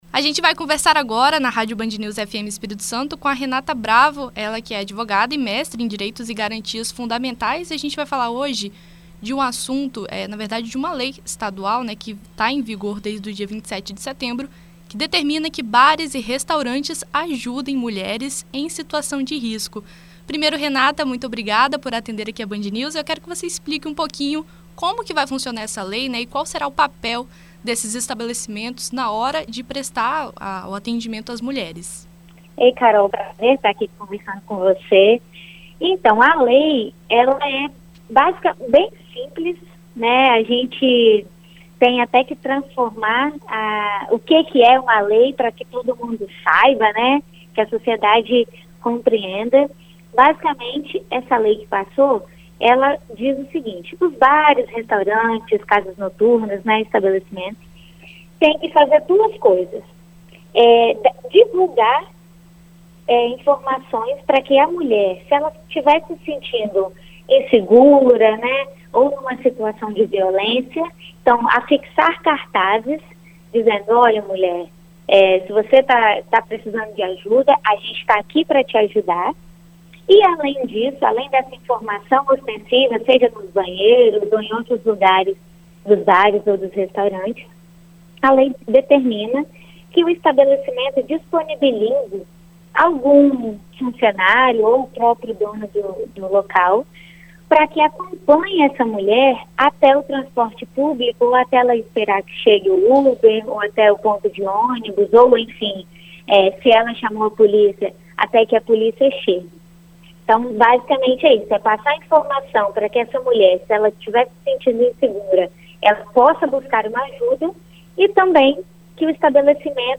Em entrevista à BandNews FM Espírito Santo nesta terça-feira (12)